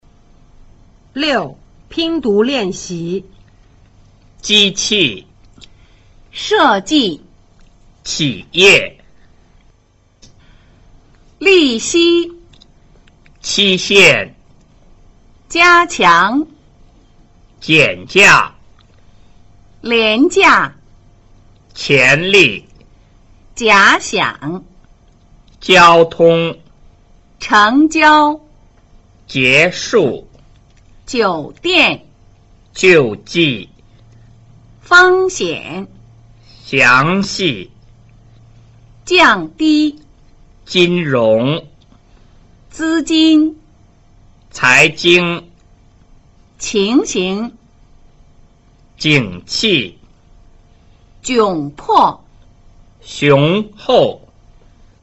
6. 拼讀練習: 自己先唸唸看, 然後老師再帶讀。